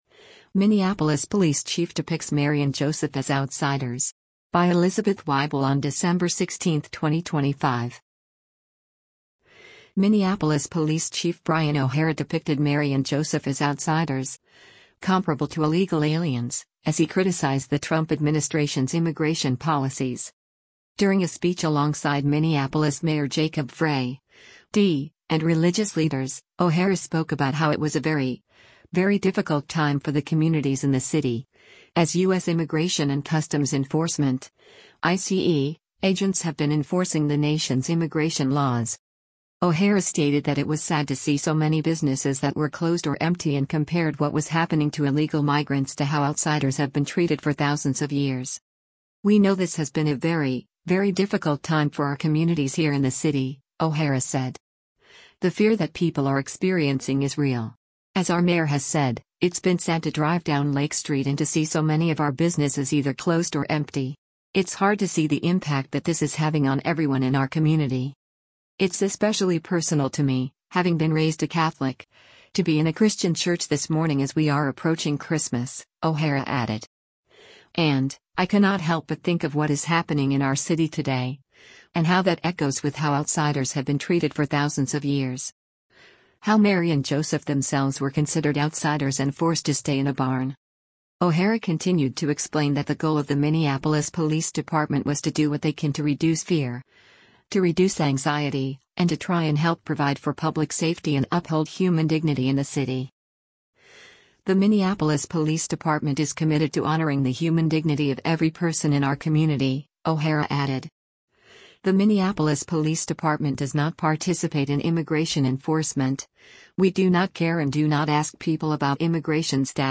During a speech alongside Minneapolis Mayor Jacob Frey (D) and religious leaders, O’Hara spoke about how it was a “very, very difficult time” for the communities in the city, as U.S. Immigration and Customs Enforcement (ICE) agents have been enforcing the nation’s immigration laws.